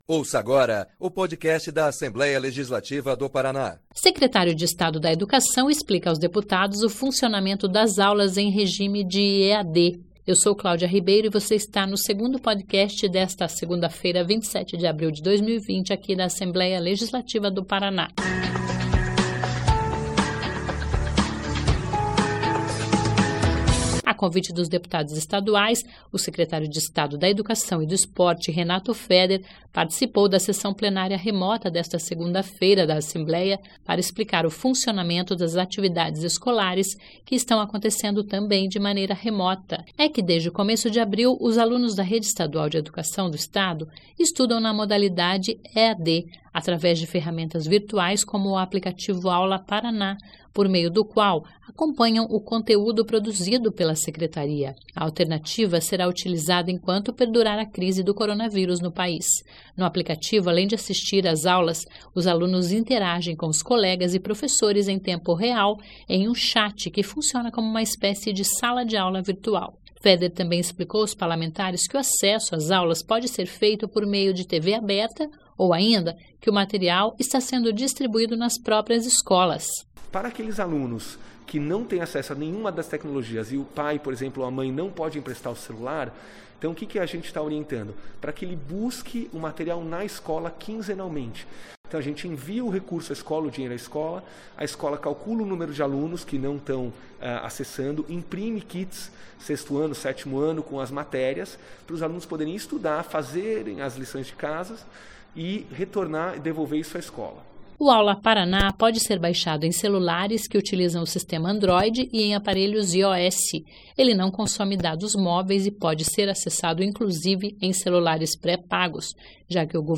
O secretário da Educação e do Esporte do Paraná, Renato Feder participou da sessão desta segunda-feira (27) e falou aos deputados sobre as soluções do governo para garantir o ensino durante a pandemia.
(Sonora)